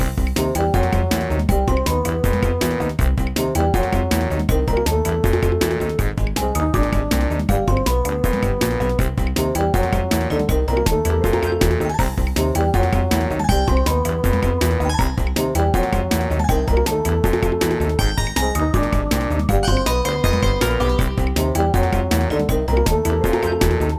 Video Game Music